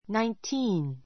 naintíːn ナイン ティ ーン